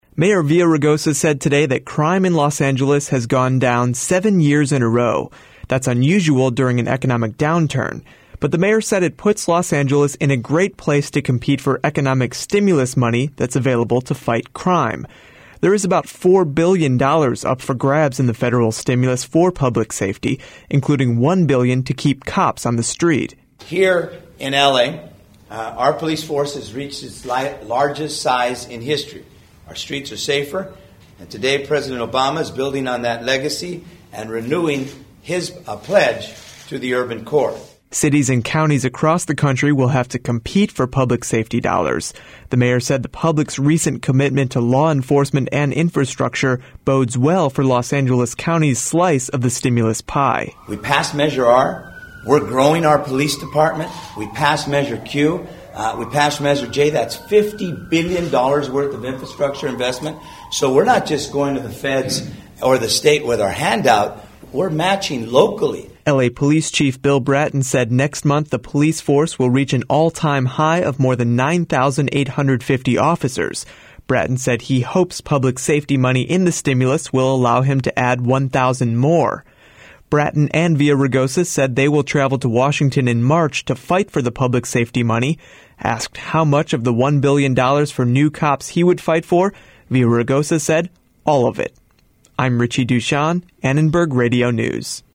Now that President Obama has signed the economic stimulus package, local officials are beginning to lay out details of how the money will be spent. Mayor Antonio Villaraigosa told a news conference this morning there is money available to help fight crime.